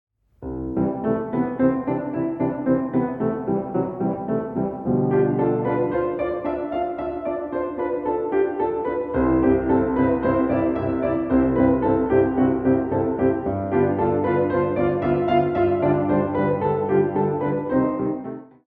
mp3Szymanowska, Maria, Vingt exercices et préludes: No. 6 in C Major, mm.1-4